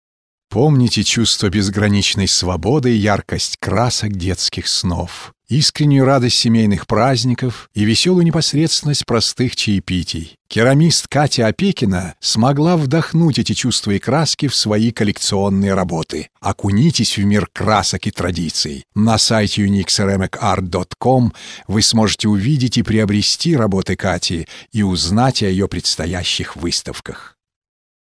Warmer samtiger Bariton
Sprechprobe: Industrie (Muttersprache):